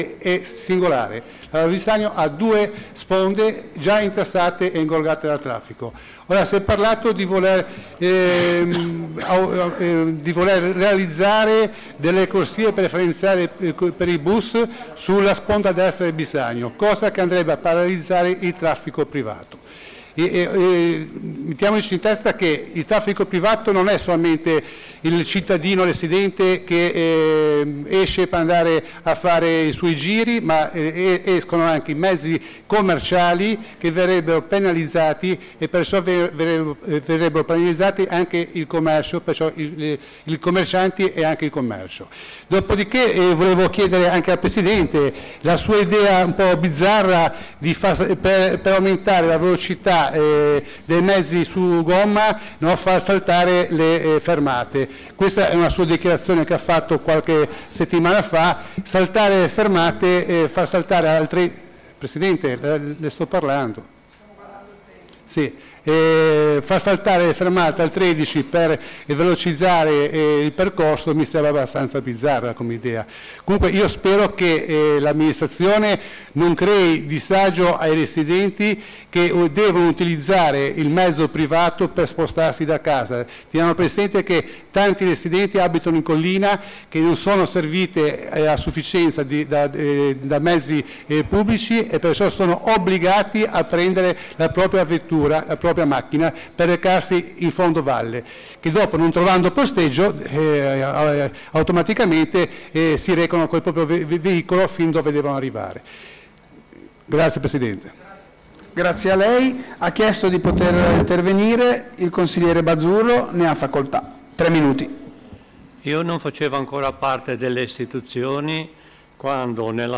Seduta di Consiglio del Municipio IV Media Val Bisagno | Comune di Genova